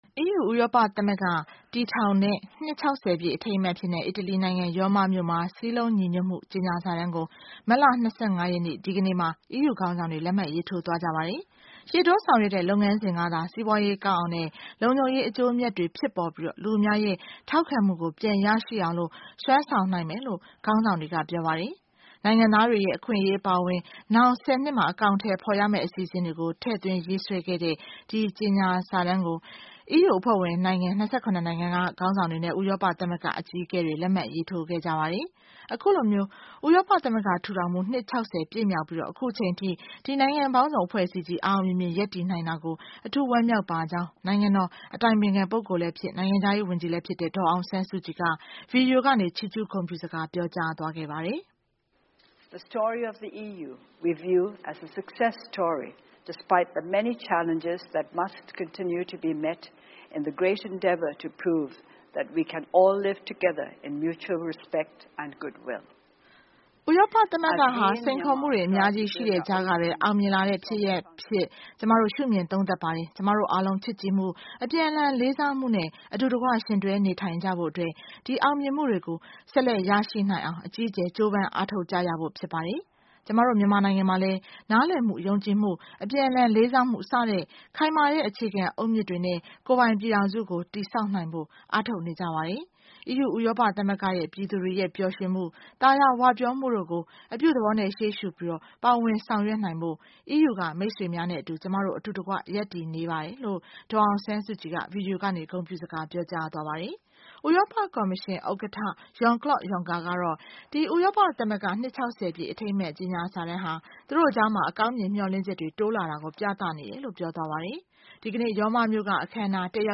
ဥရောပ သမဂ္ဂ ထူထောင်မှု နှစ် ၆၀ ပြည့် မြောက်ပြီး အခုအချိန်အထိ ဒီနိုင်ငံပေါင်းစုံ အဖွဲ့အစည်းကြီး အောင်အောင်မြင်မြင် ရပ်တည်နိုင်တာကို အထူးဝမ်းမြောက်ပါကြောင်း နိုင်ငံတော် အတိုင်ပင်ခံပုဂ္ဂိုလ်လည်း ဖြစ် နိုင်ငံခြားရေးဝန်ကြီးလည်း ဖြစ်တဲ့ ဒေါ်အောင်ဆန်းစုကြည်က Video ကနေ ချီးကျူးဂုဏ်ပြုစကား ပြောကြားသွားပါတယ်။